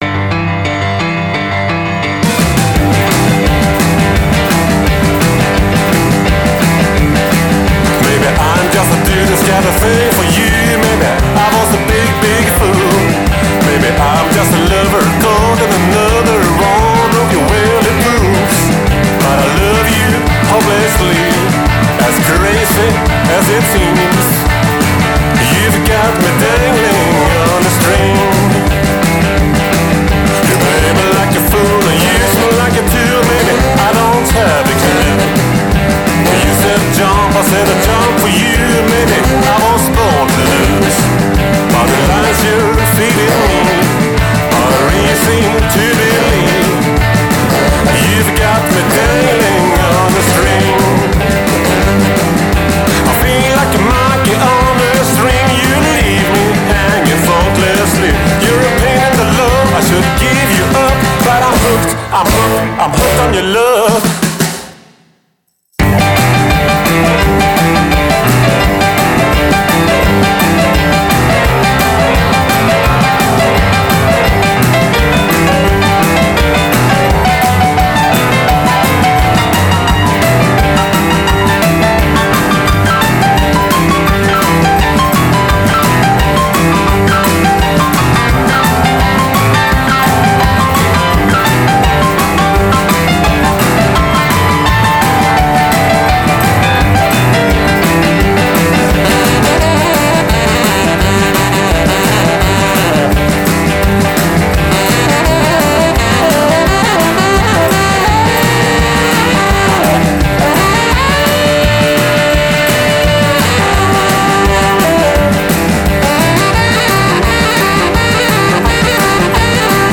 Svängig 50-tals rock, rockabilly, blues och rock'n'oll.